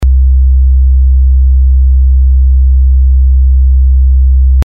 sinus_65Hz - LASA
sinus_65Hz.mp3